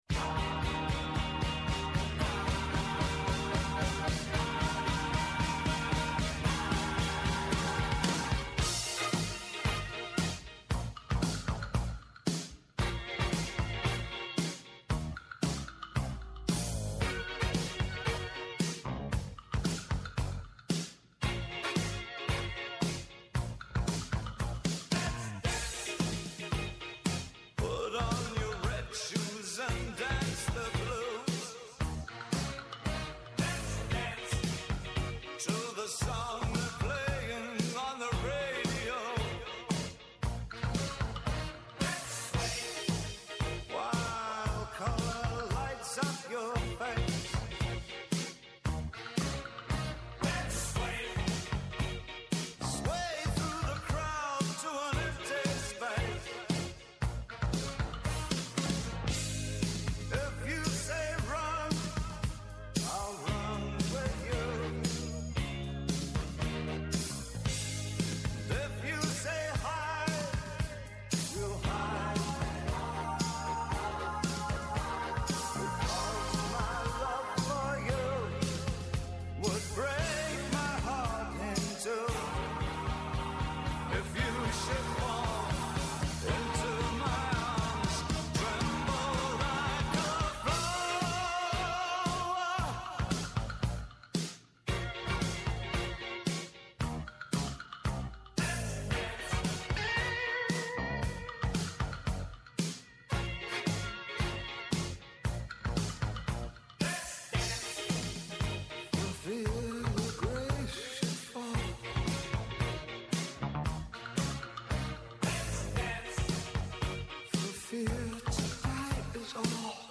Kαλεσμένοι στην εκπομπή είναι κυβερνητικοί αξιωματούχοι, επιχειρηματίες, αναλυτές, τραπεζίτες, στελέχη διεθνών οργανισμών, πανεπιστημιακοί, φοροτεχνικοί και εκπρόσωποι συνδικαλιστικών και επαγγελματικών φορέων, οι οποίοι καταγράφουν το σφυγμό της αγοράς και της οικονομίας.